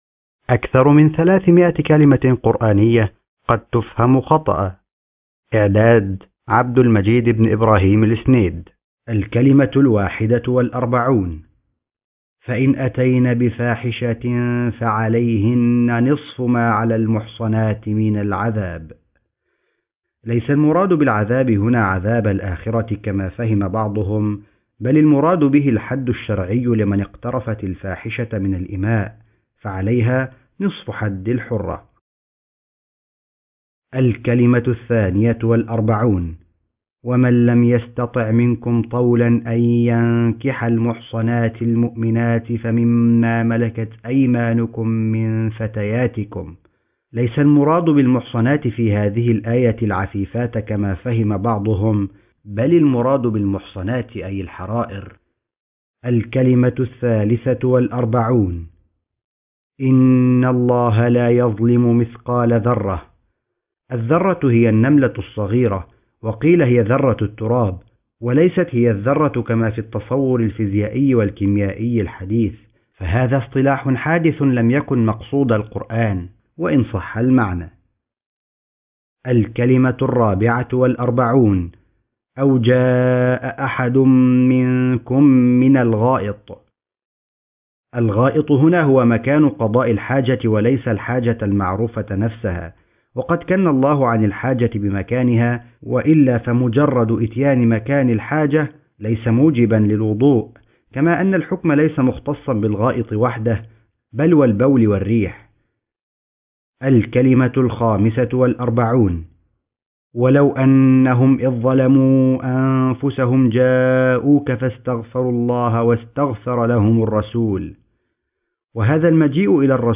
أكثر من 300 كلمة قرآنية قد تفهم خطأ ( كتاب صوتي مقروء )